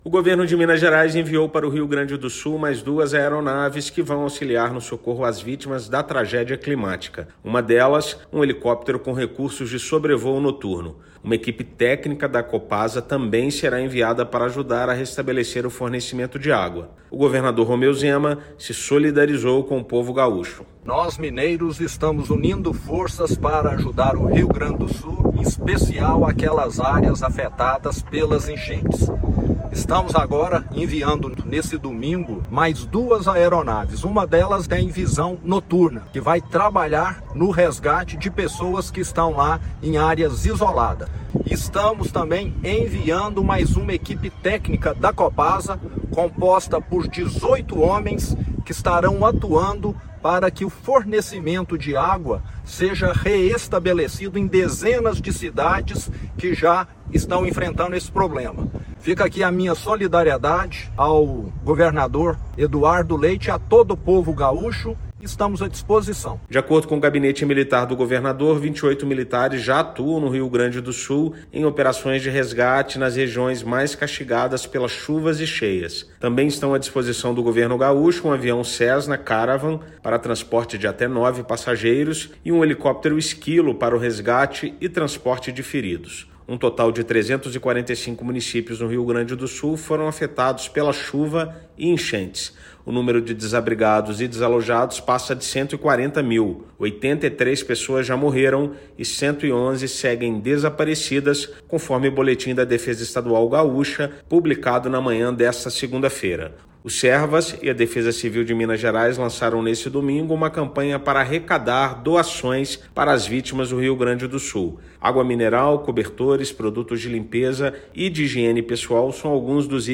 Equipe do Corpo de Bombeiros de Minas, integrada por 28 militares, já trabalha na região em operações de buscas. Ouça matéria de rádio.